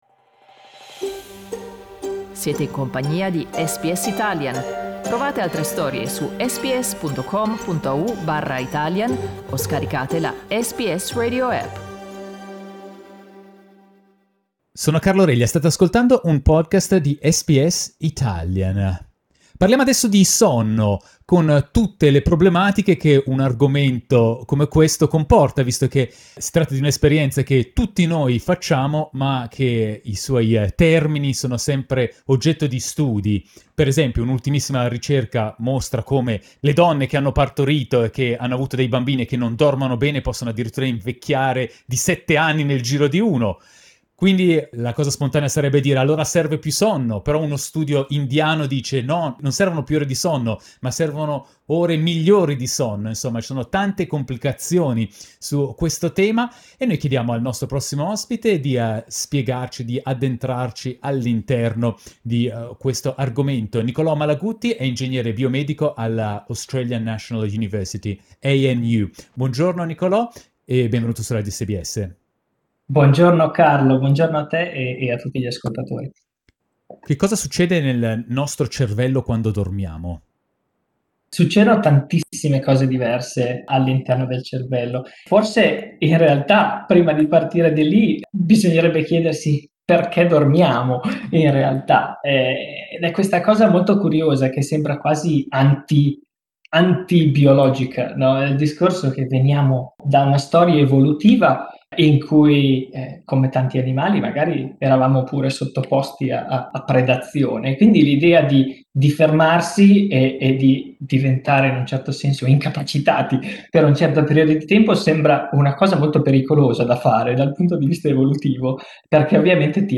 Ci racconta cosa cosa succede quando dormiamo, ai microfoni di SBS Italian.